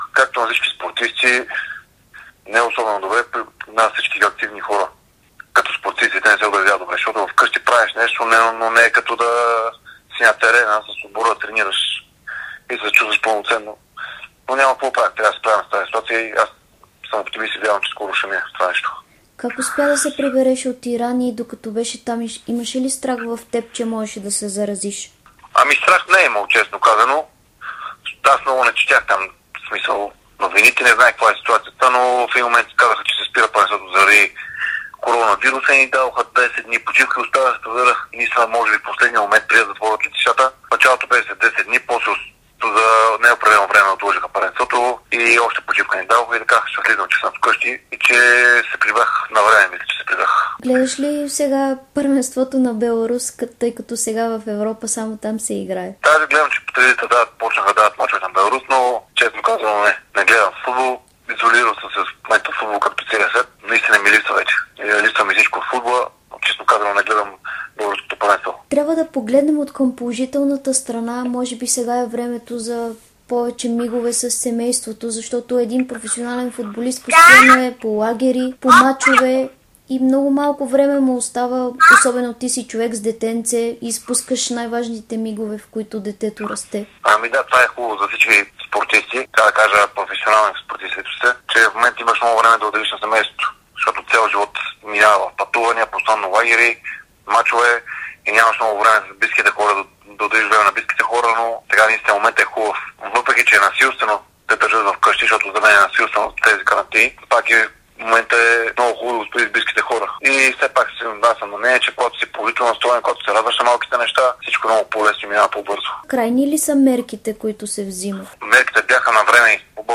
Защитникът Николай Бодуров даде интервю за dsport и Спортното шоу на Дарик, в което сподели как се чувства по време на изолацията и без футбол. Той коментира и ситуацията с неговия ирански отбор и възможността да продължи кариерата си в него.